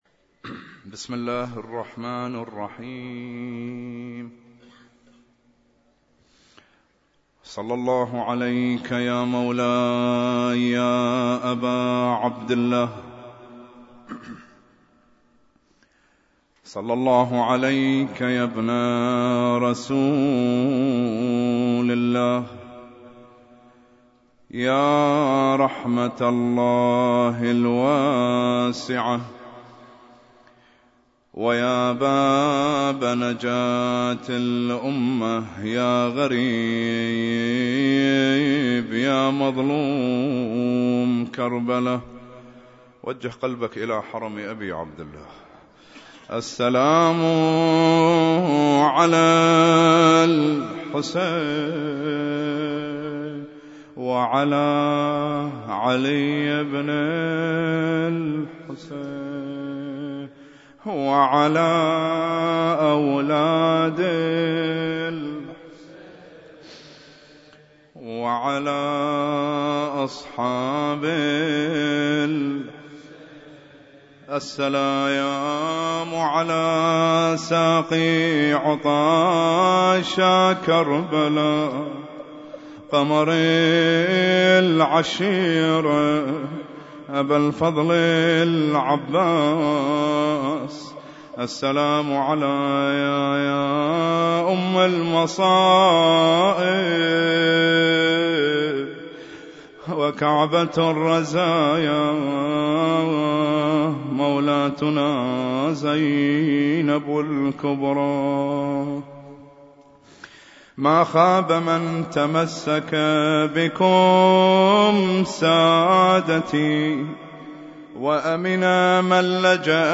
Husainyt Alnoor Rumaithiya Kuwait
المحاضرات